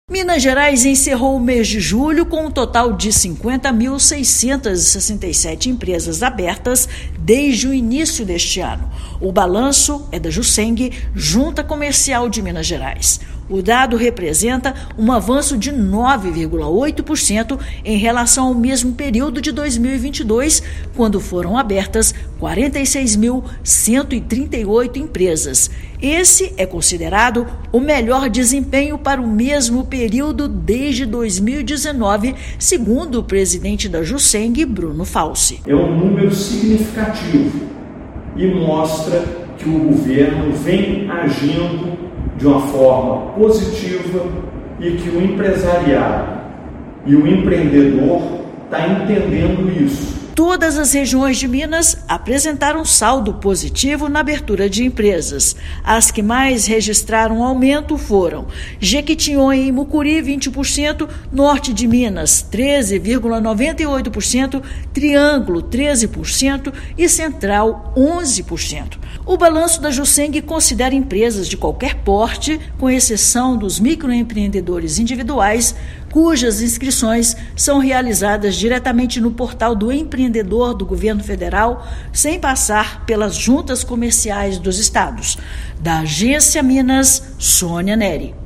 [RÁDIO] Minas Gerais ultrapassa marca das 50 mil empresas abertas no ano
Balanço da Jucemg mostra ainda que, somente em julho, 7.824 empreendimentos foram abertos em todas as regiões do estado. Ouça matéria de rádio.